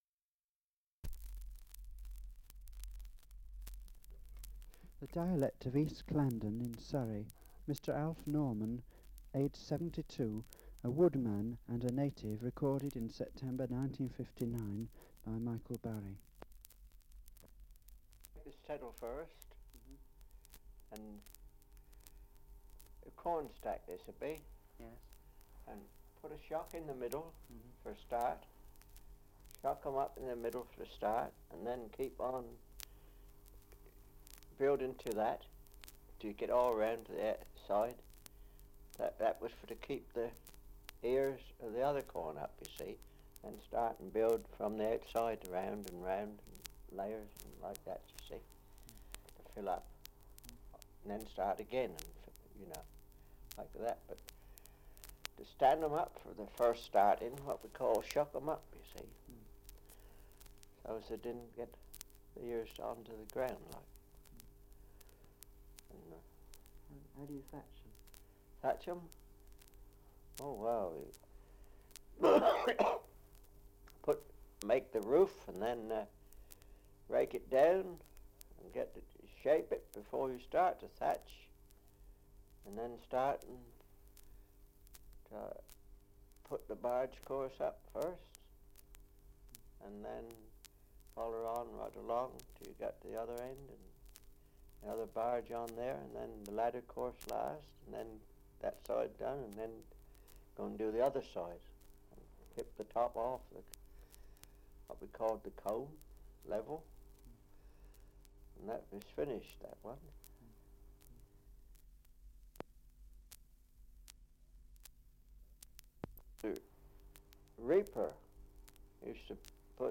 Survey of English Dialects recording in East Clandon, Surrey
78 r.p.m., cellulose nitrate on aluminium